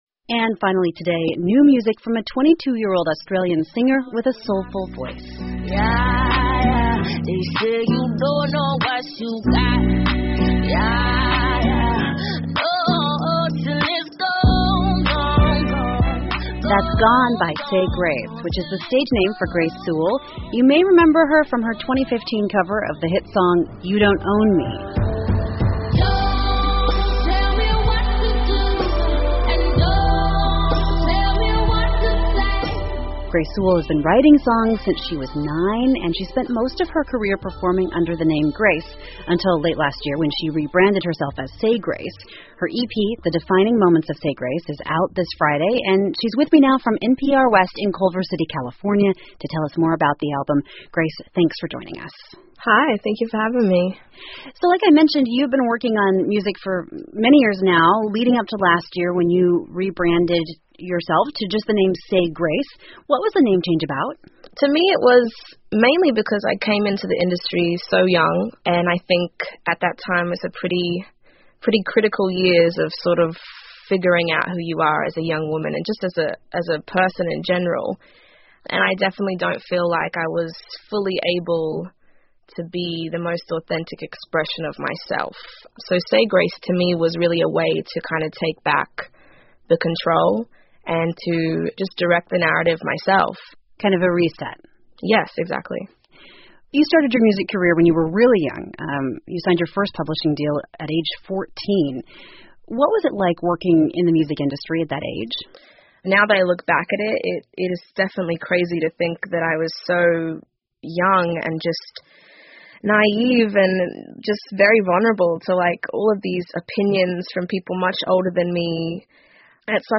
美国国家公共电台 NPR Interview